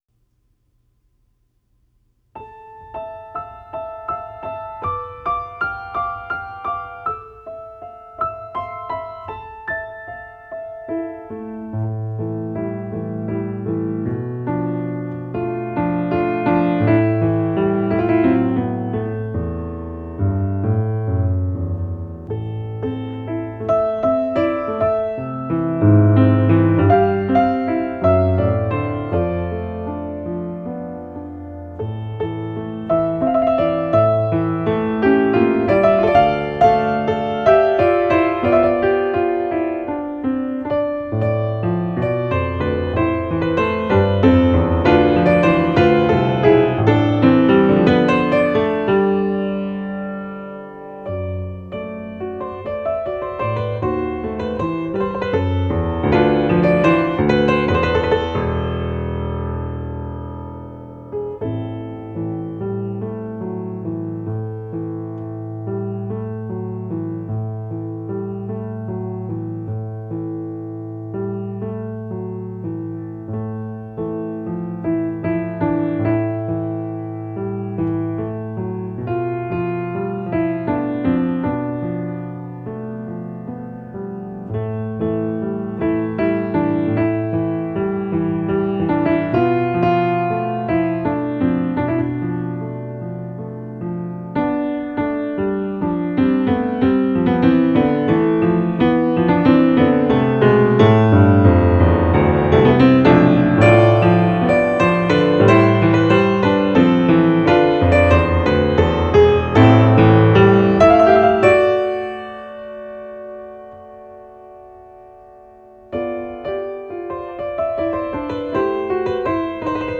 Recorded in 2005, USA.
Folk song from Kordestan